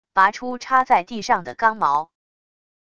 拔出插在地上的钢矛wav音频